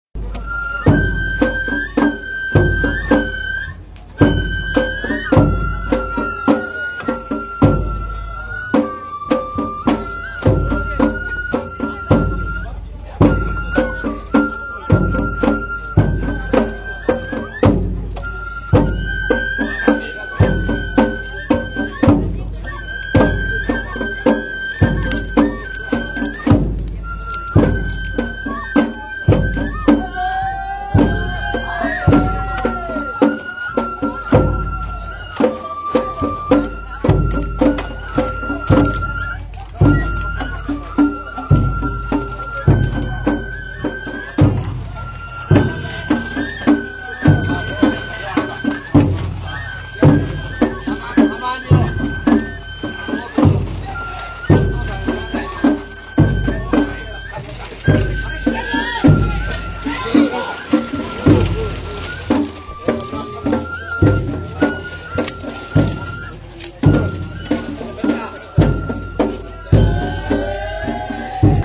尾張地方の山車囃子は、能楽を基本に編曲された楽曲が多く、主に大太鼓、締太鼓、小鼓、そして笛（能管と篠笛）によって演奏されます。
曳き出しの時に演奏される。